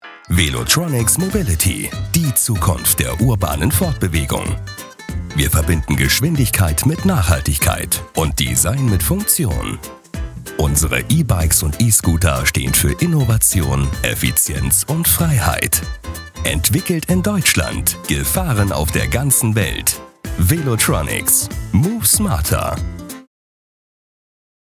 • Eigenes Tonstudio
Imagefilm - dynamisch kraftvoll
Imagefilm-dynamisch-kraftvoll.mp3